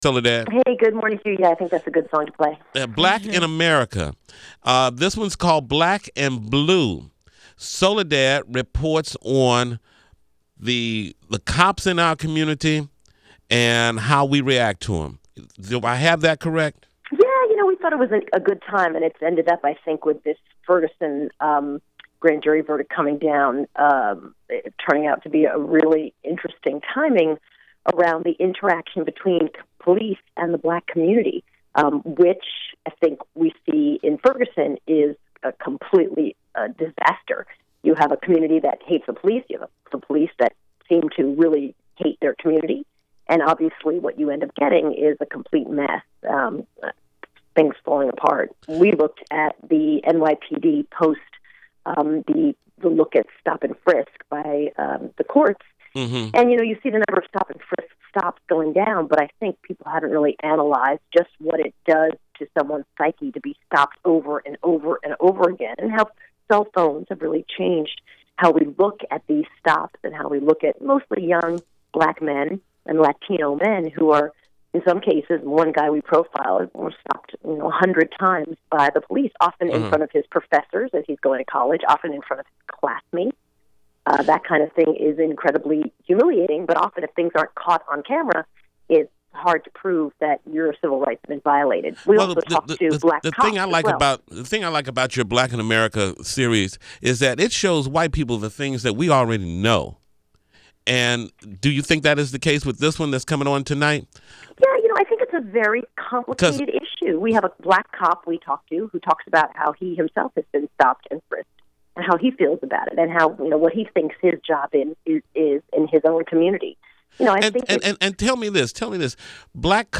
Soledad O’Brien’s Black In America series continues on CNN! She talks about the latest installment on The Tom Joyner Morning Show Tuesday, Nov. 18th!